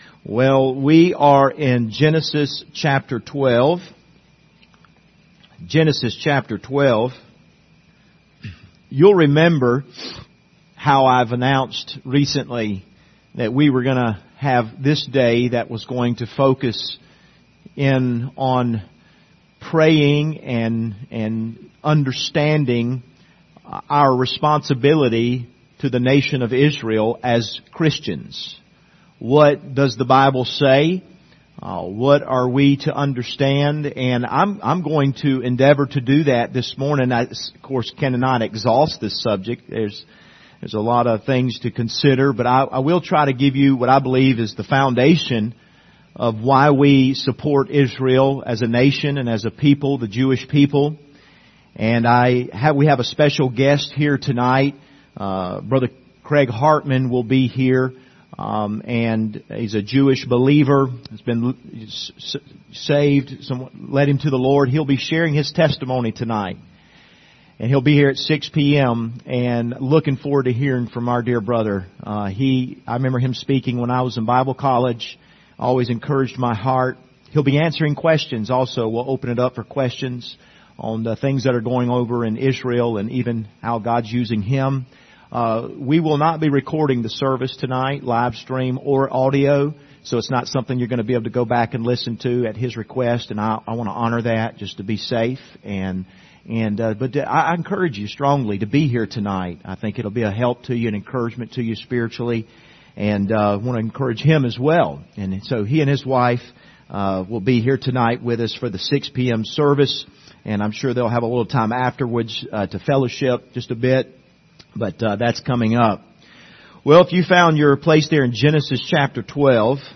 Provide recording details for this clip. Passage: Genesis 12:1-3 Service Type: Sunday Morning View the video on Facebook Topics